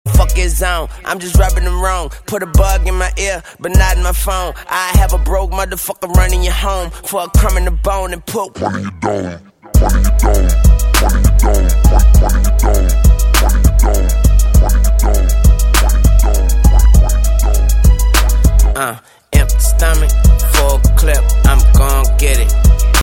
rap ringtones
remix ringtones